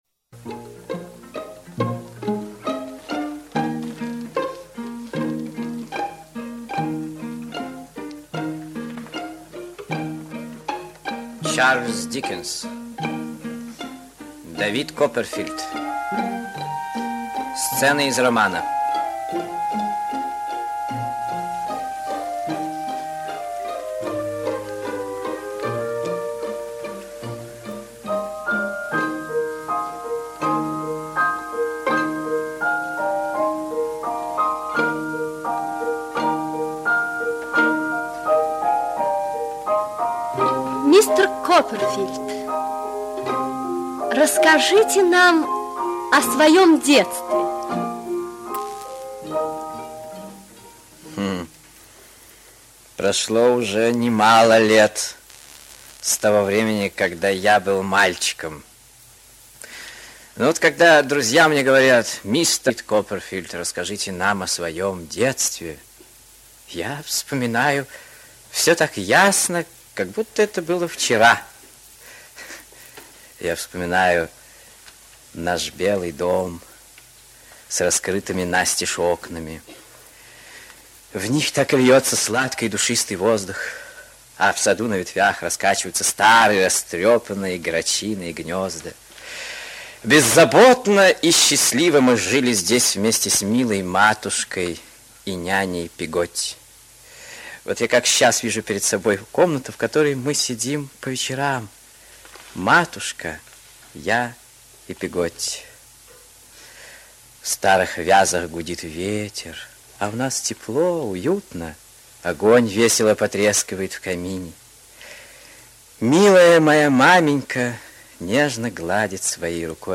Жизнь Дэвида Копперфилда - аудио рассказ Диккенса - слушать онлайн